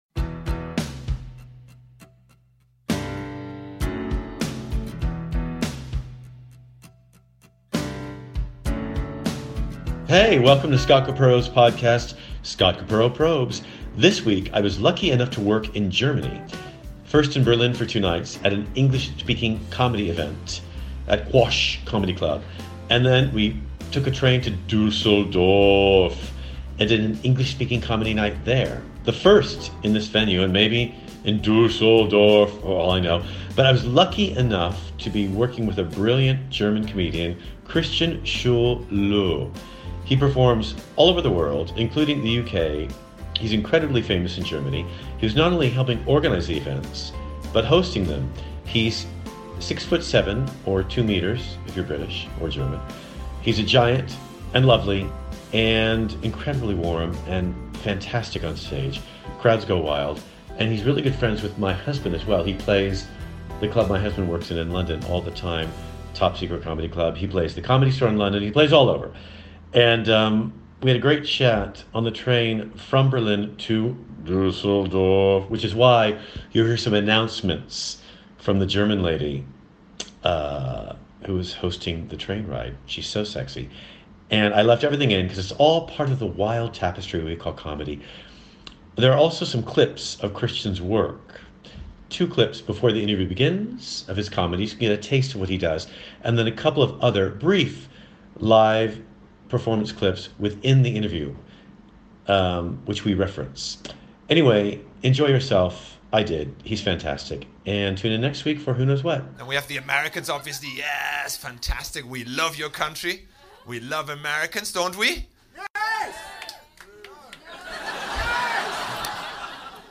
Comedy Interviews
Heya, this week we’re on a train (clean, quiet and fast-moving cuz we’re in Germany) between Berlin and Dusseldorf